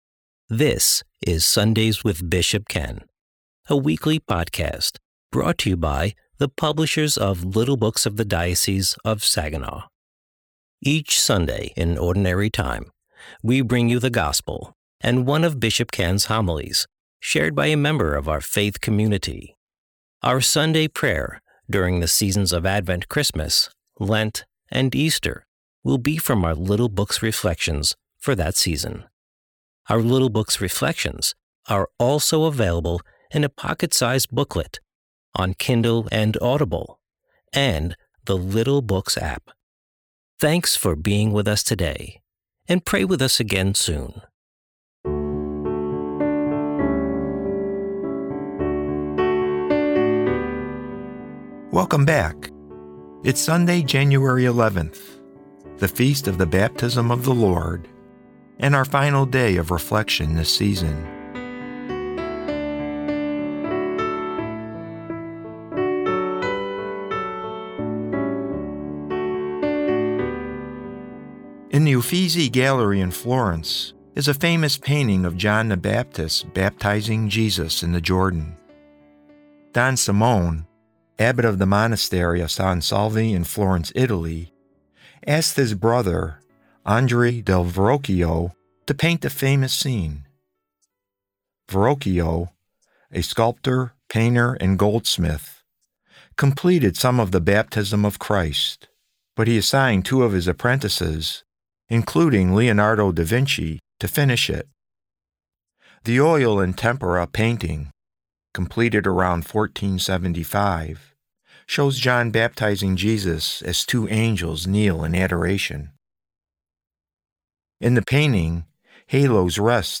Today's episode of Sunday's with Bishop Ken is a reading from The Little Blue Book: Advent and Christmas 2025. Join us as we reflect on the Baptism of the Lord.